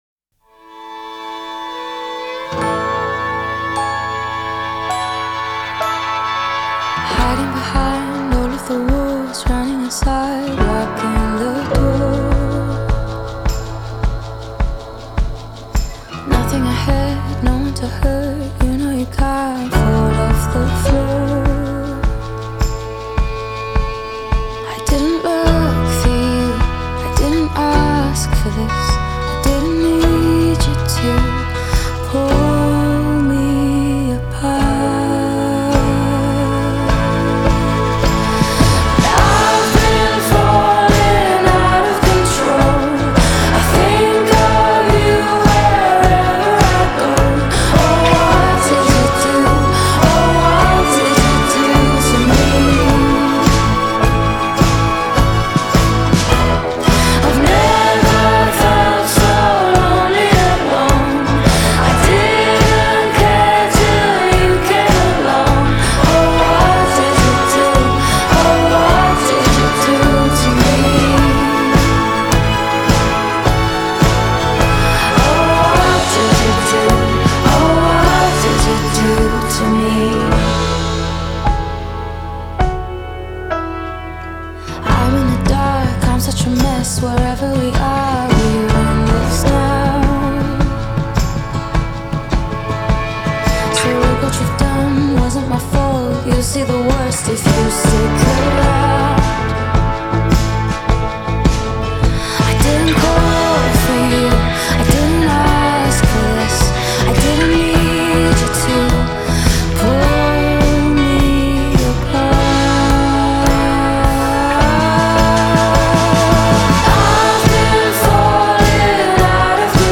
آهنگ پاپ